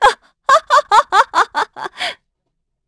Isolet-Vox_Happy3_kr.wav